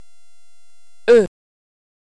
ê = si pronuncia come la "e" di "je (io)" francese
pronuncia della ê.mp3